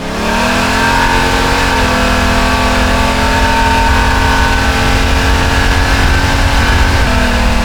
v8_turbo_on_loop1.wav